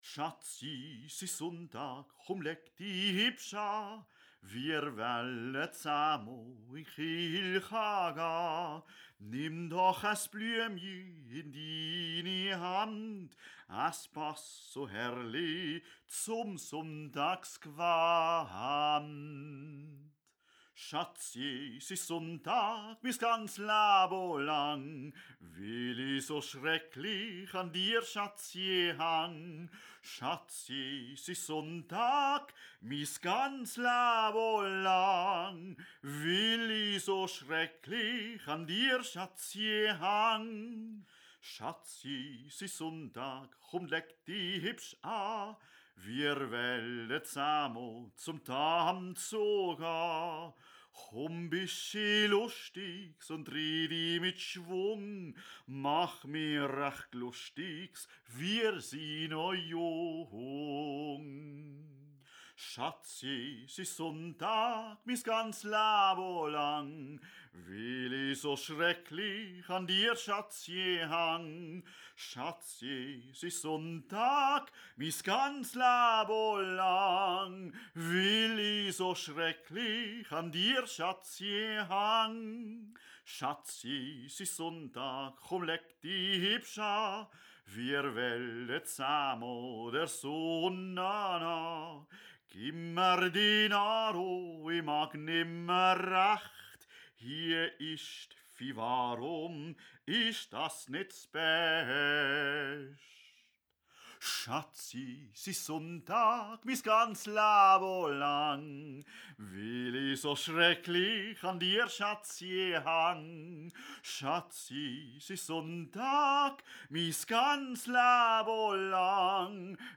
__ für den Hausgebrauch eingerichtet
Hauptstimme solo
Haupt- und Nebenstimme gemeinsam